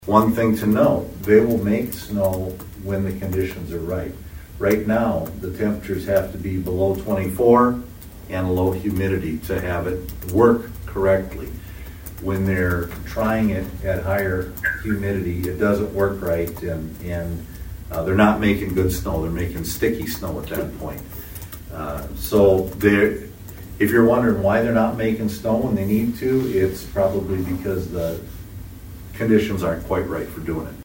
ABERDEEN, S.D.(HubCityRadio)- Toward the end of Monday’s City Council meeting, Aberdeen City Manager Robin Bobzien addressed couple issues dealing with the city of Aberdeen.